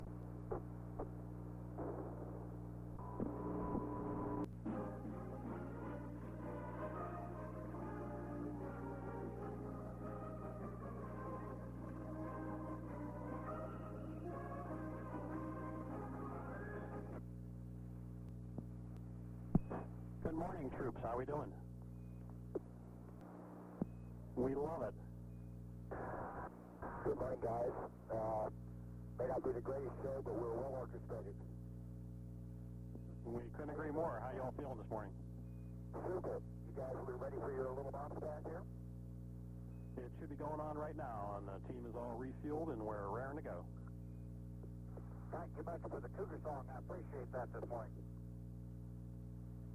Fight Song broadcast to the Space Shuttle Challenger in 1983